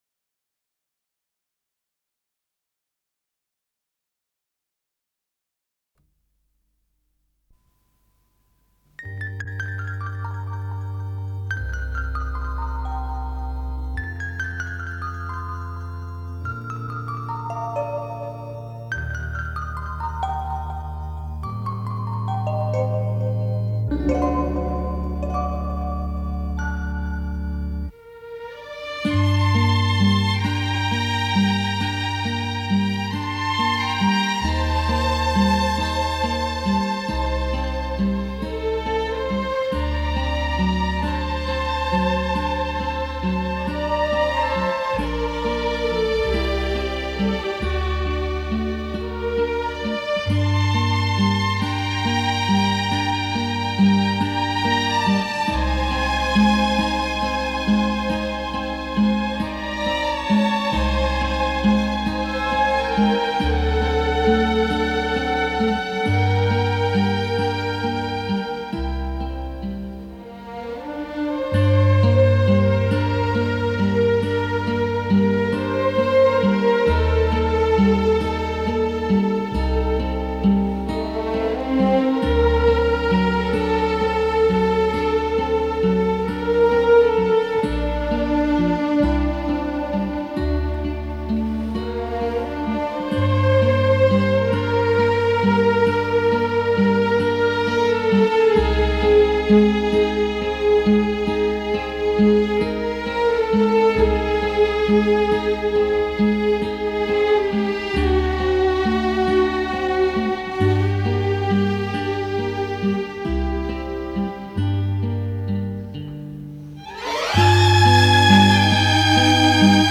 с профессиональной магнитной ленты
Вокальный ансамбль
Скорость ленты38 см/с
МагнитофонМЭЗ-109М